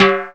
TIMBALE.wav